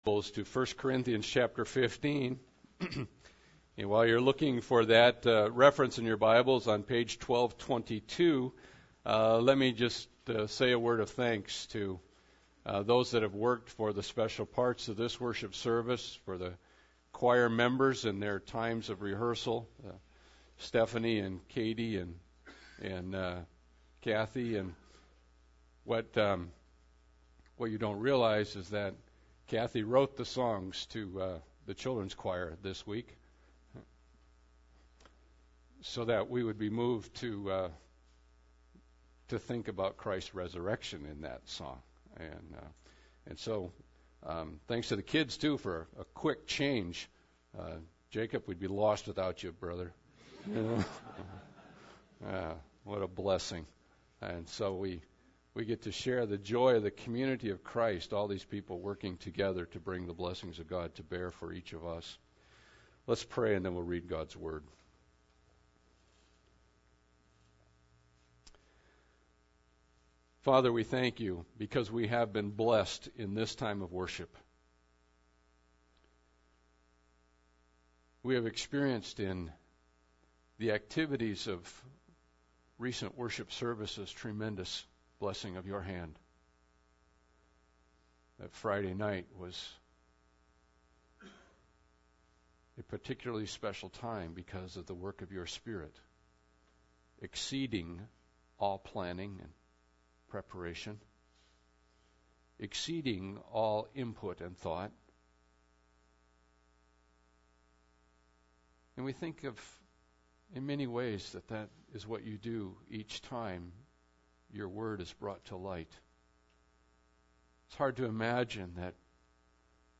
Passage: 1 Corinthians 15 Service Type: Sunday Service